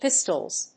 /ˈpɪstʌlz(米国英語)/